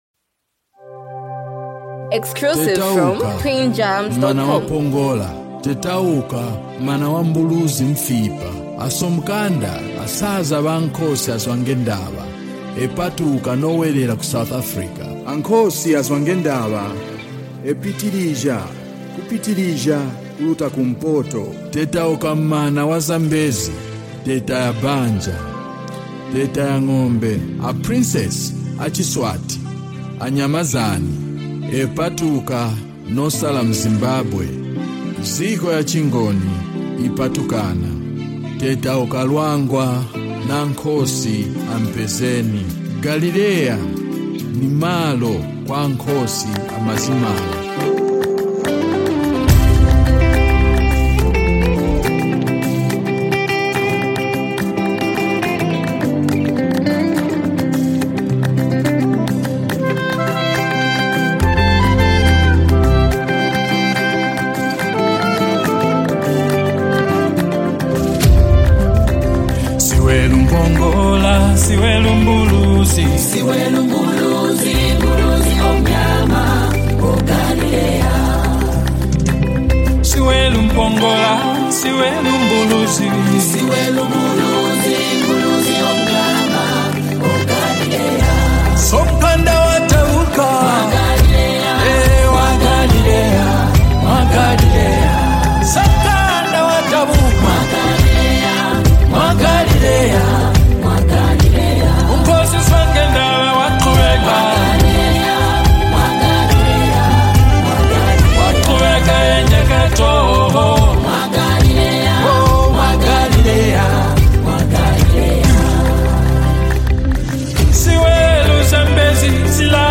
spiritually uplifting gospel song
The rich instrumentation